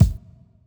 MB Kick (4).wav